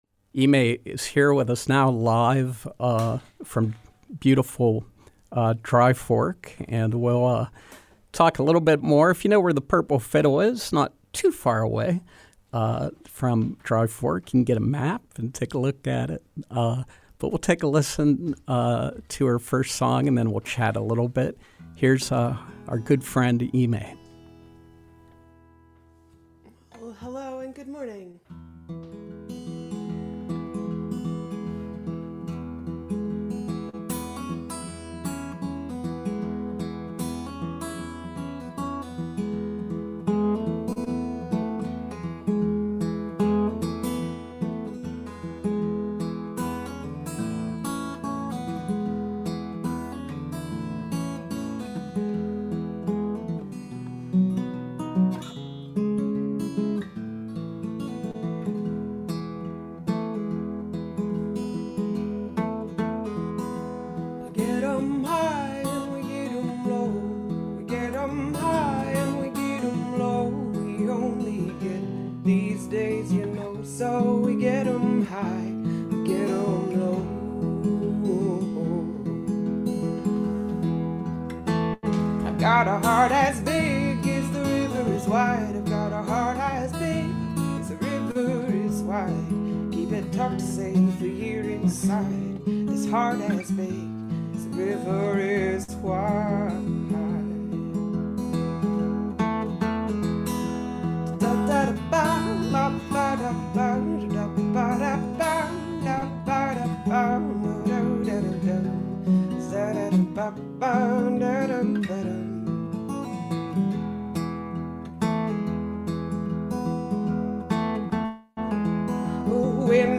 live from Dry Fork, West Virginia.